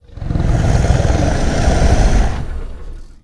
c_wnaga_hit3.wav